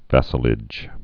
(văsə-lĭj)